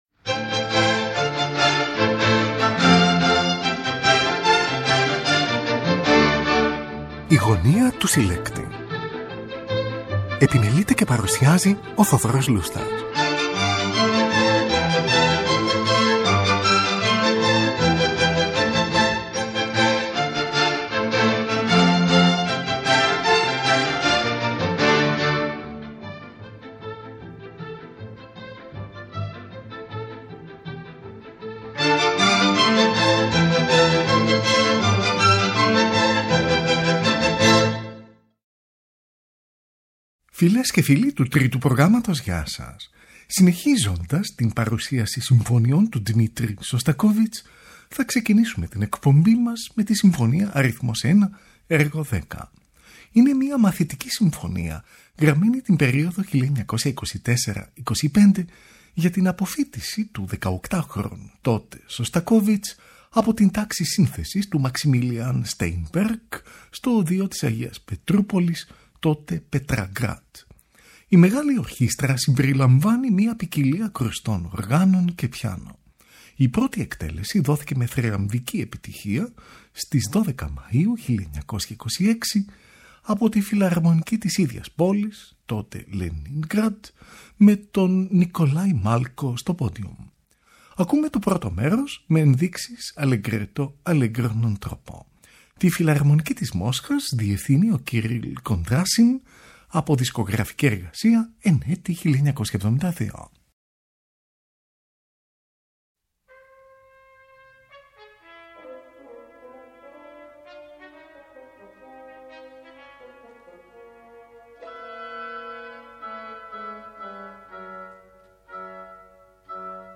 για Πιάνο